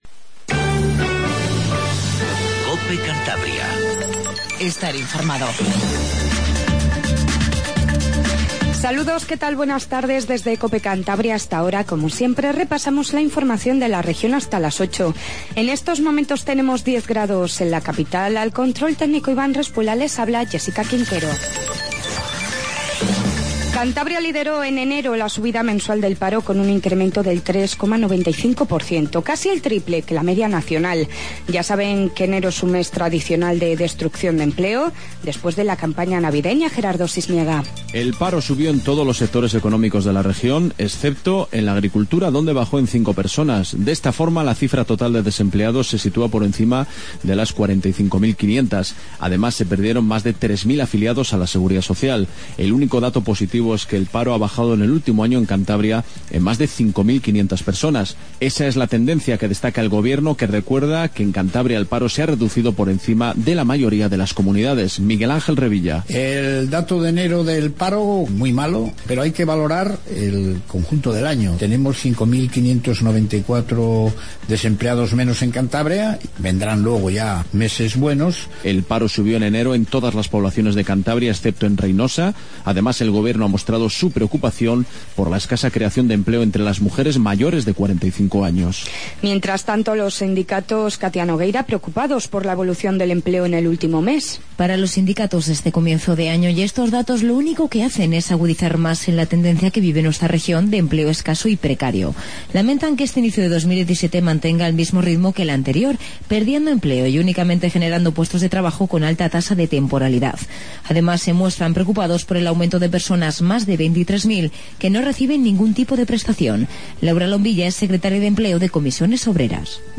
Noticias